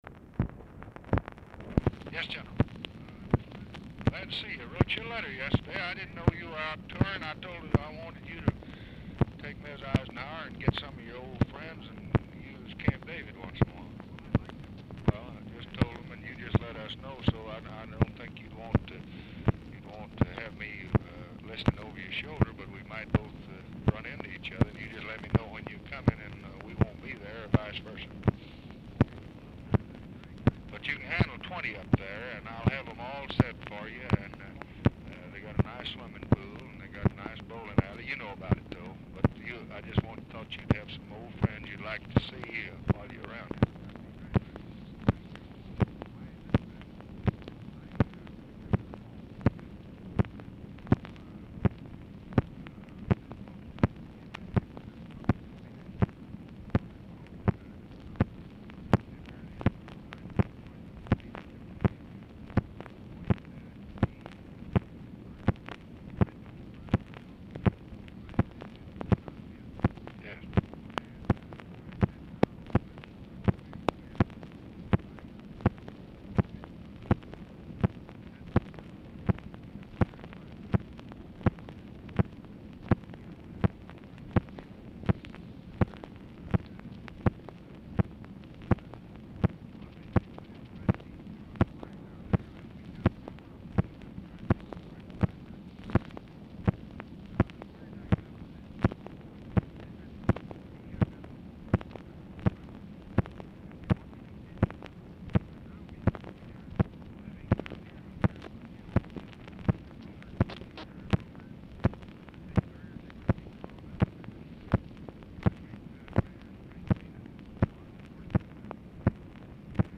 Telephone conversation # 8373, sound recording, LBJ and DWIGHT EISENHOWER, 7/23/1965, 11:45AM | Discover LBJ
POOR SOUND QUALITY
Format Dictation belt
Location Of Speaker 1 Oval Office or unknown location